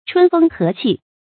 春風和氣 注音： ㄔㄨㄣ ㄈㄥ ㄏㄜˊ ㄑㄧˋ 讀音讀法： 意思解釋： 春天和煦的春風吹拂著人們。